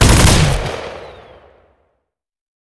fire3.ogg